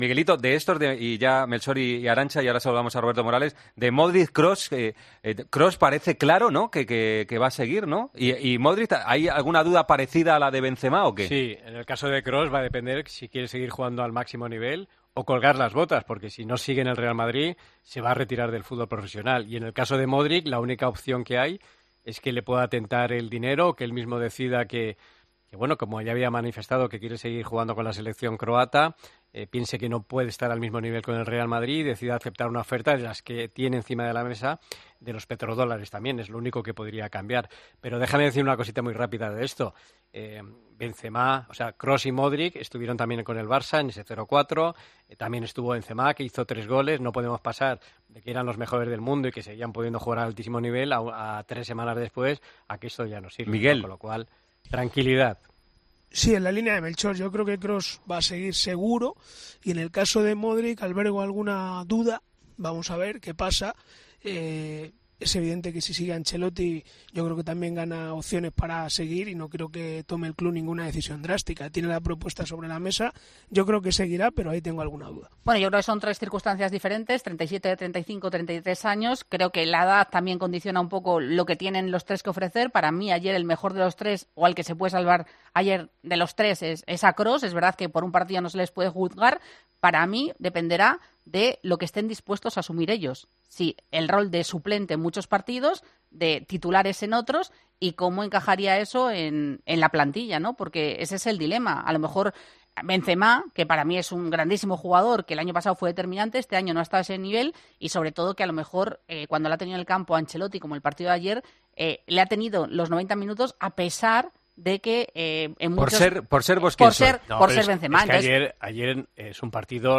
DEBATE EN 'DEPORTES COPE'